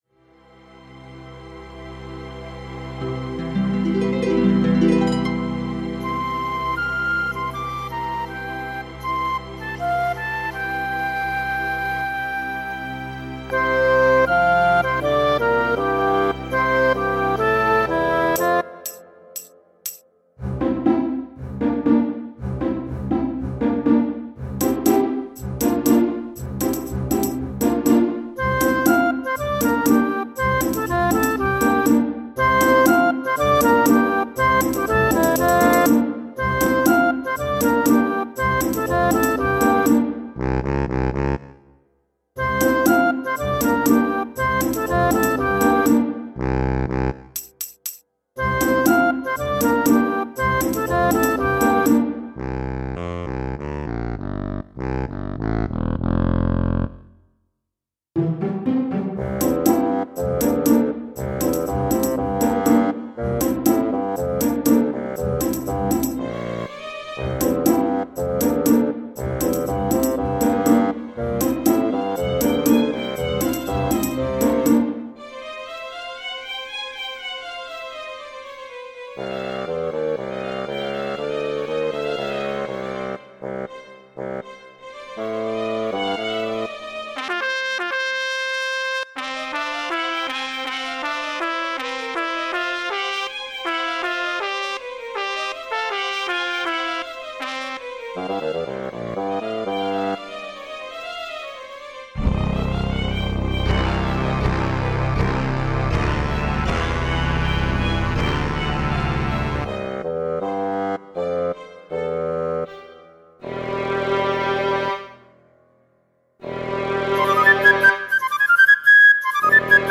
Eh beh elle est chouette la compo, bien typée VGM avec les instru quasi-Edirol !
mrgreen Les cordes sont de sonatina, et le reste c'est du sampletank 2.5 :)